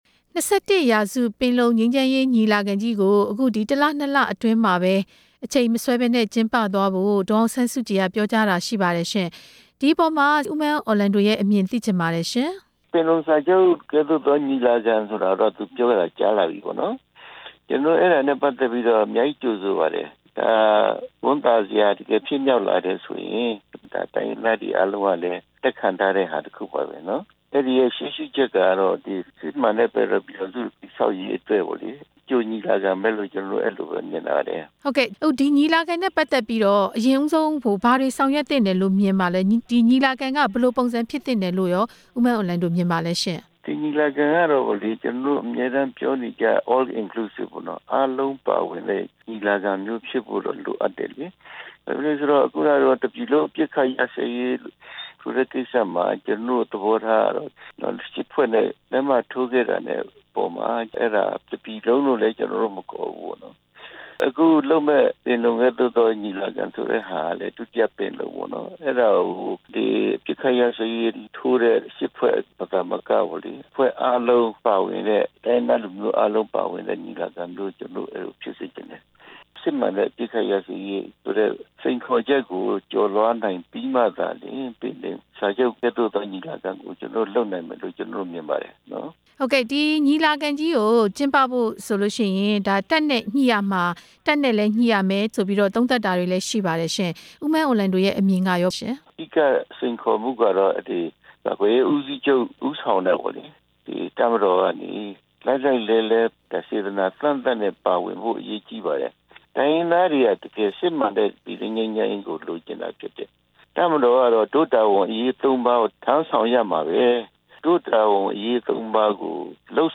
၂၁ ရာစု ပင်လုံညီလာခံ အစီအစဉ်၊ နိုင်ငံတကာ ကရင်အမျိုးသား အဖွဲ့အစည်းနဲ့ မေးမြန်းချက်